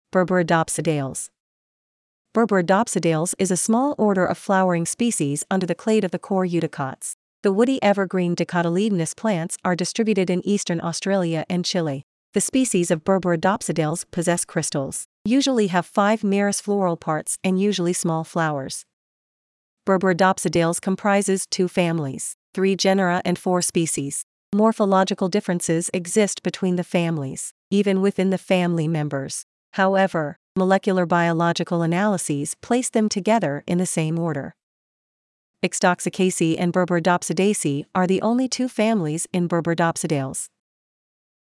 Berberidopsidales-Pronunciation.mp3